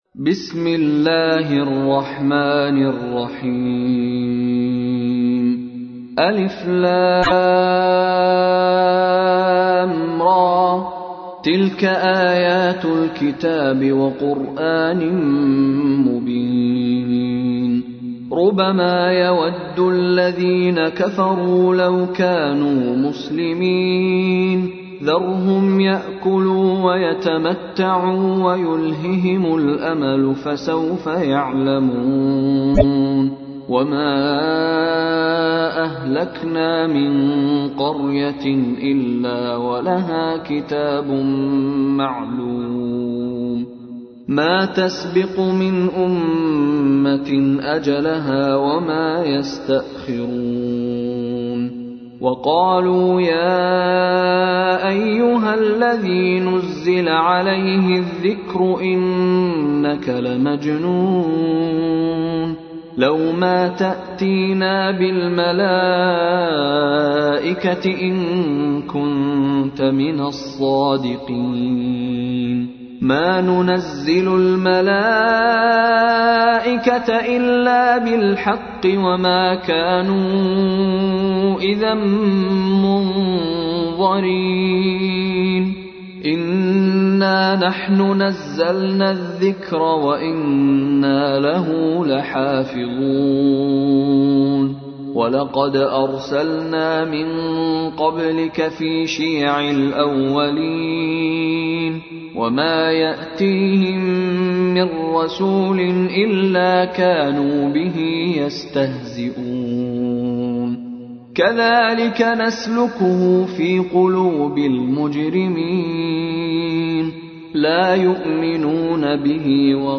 تحميل : 15. سورة الحجر / القارئ مشاري راشد العفاسي / القرآن الكريم / موقع يا حسين